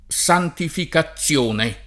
santificazione [ S antifika ZZL1 ne ] s. f.